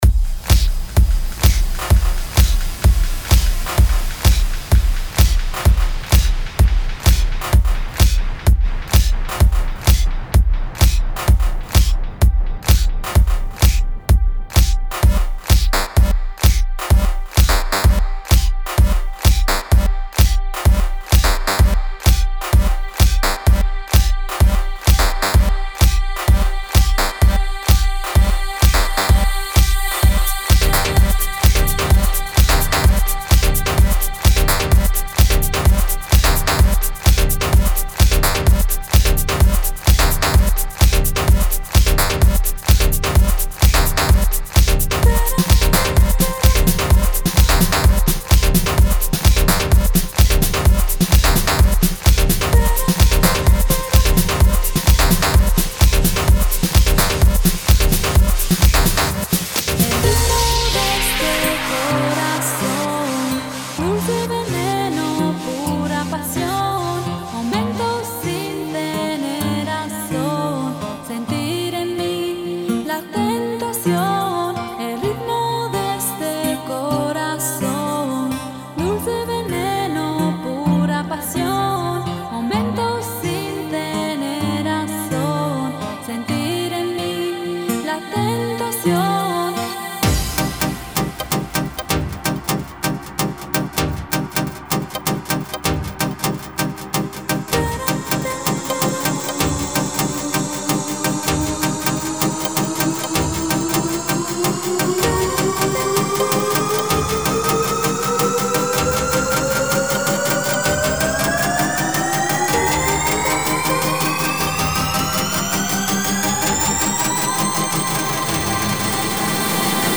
O piesă remixată